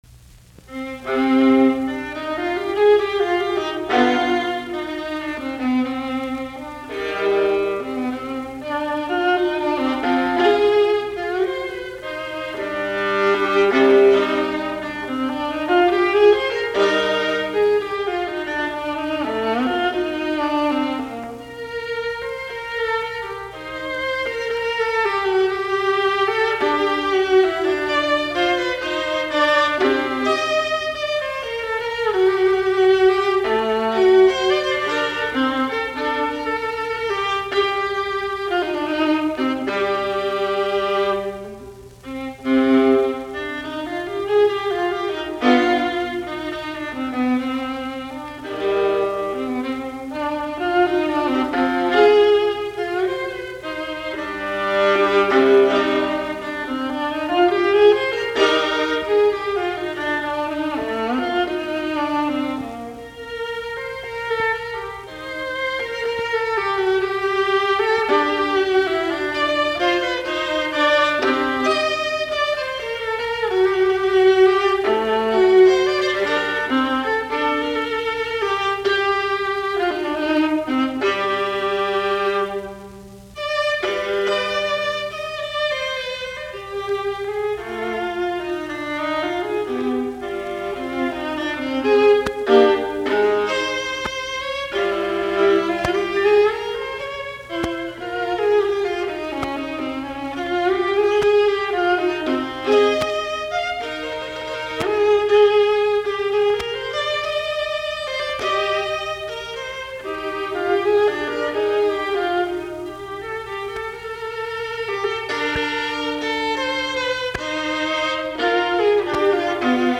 alttoviulu